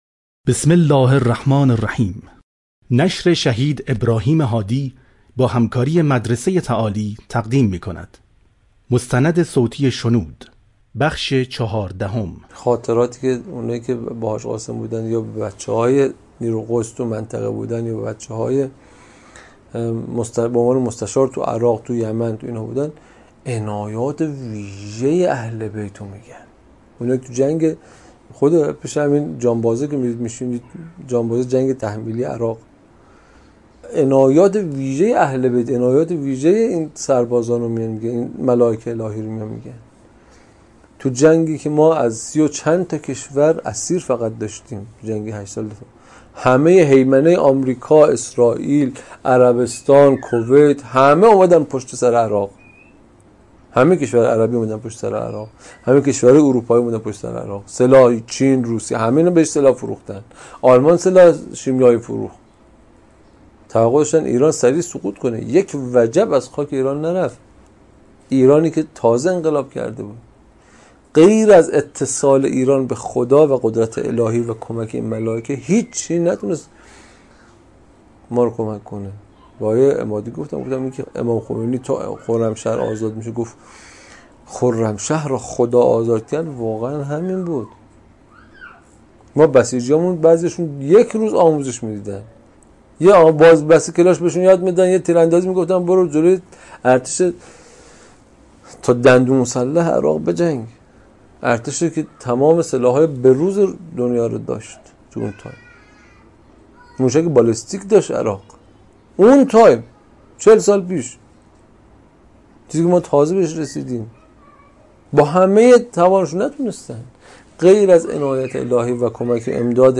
مستند صوتی شنود - جلسه 14 (چهاردهم)
تجربه نزدیک به مرگ یک مسئول امنیتی در بیمارستان که روحش از بدنش جدا شده و می‌ توانسته حقایق و باطن اتفاقات را ببیند، در این مستند صوتی از مشاهداتش می‌ گوید.